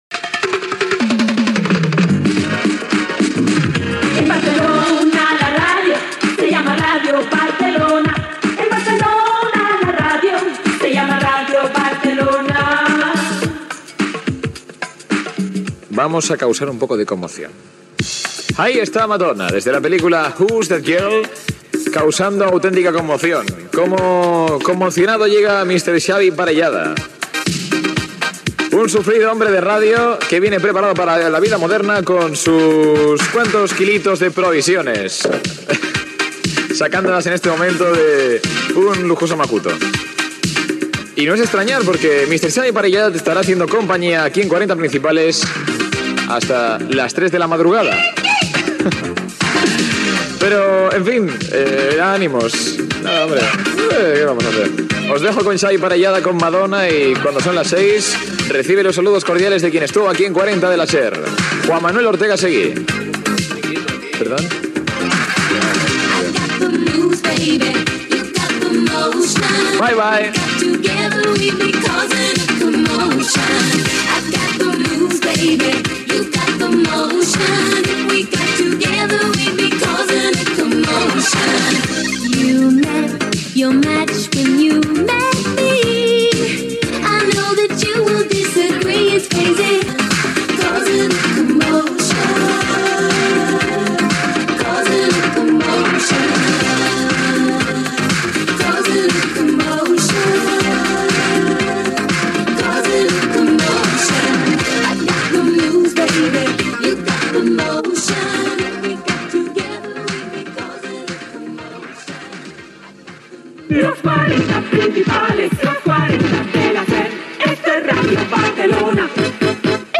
Relleu entre els locutors de "Los 40 Principales".
Musical